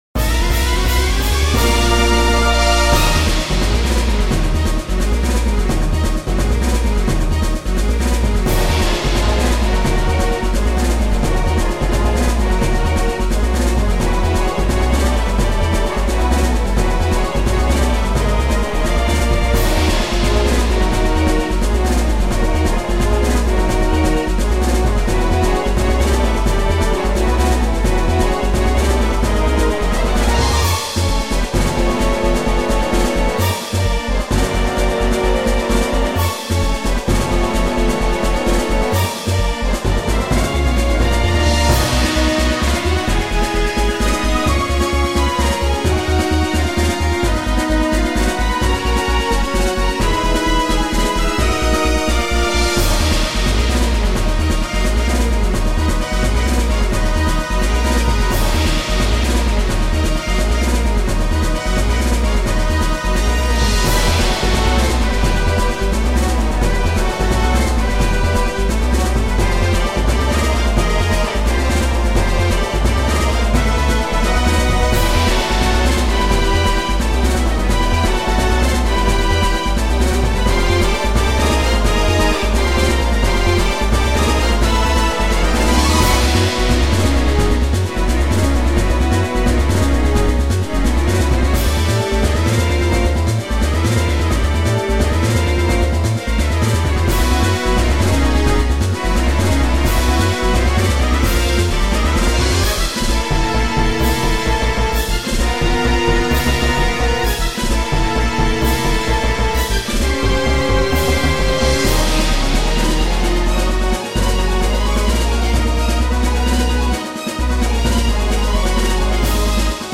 I made a boss theme where Luna and the Mane Six battle the Tantabus, and save Equestria.